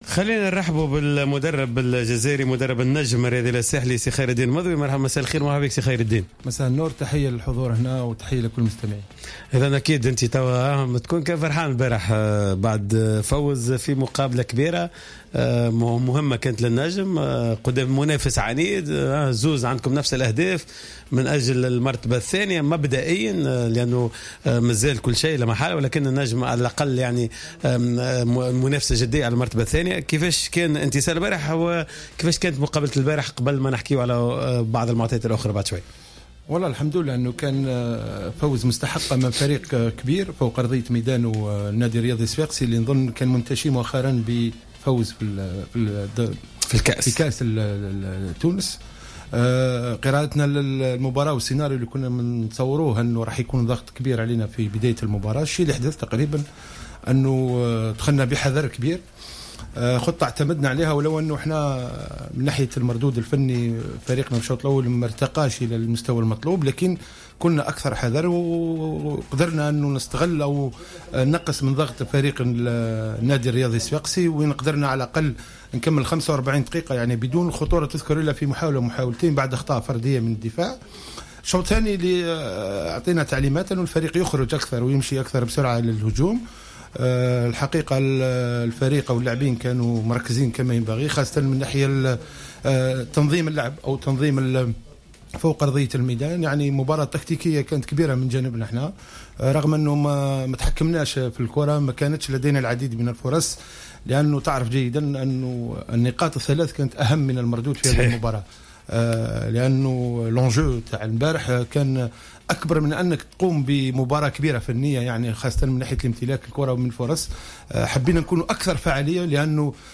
تحدث مدرب النجم الساحلي خير الدين مضوي ضيف حصة "راديو سبور" على وضعية الفريق حاليا في البطولة و إستعداداته لمواجهة الدور التمهيدي لمسابقة رابطة أبطال إفريقيا أمام فريق بلاتو يونايتد النيجيري.